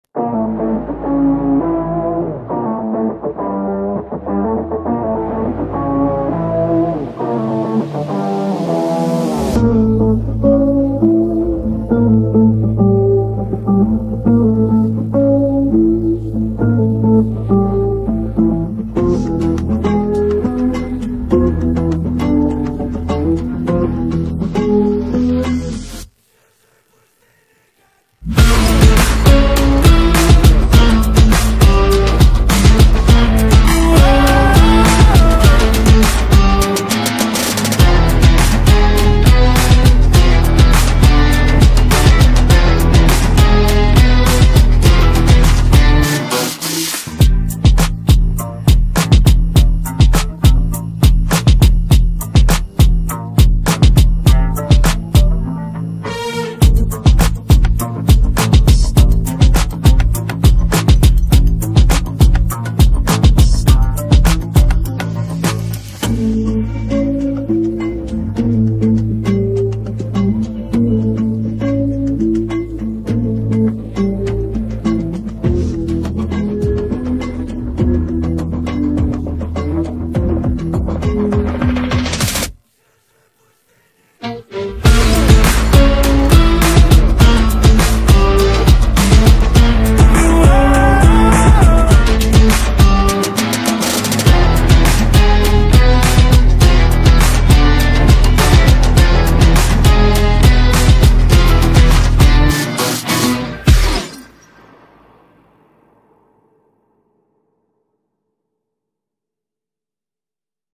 Минусы песен 2026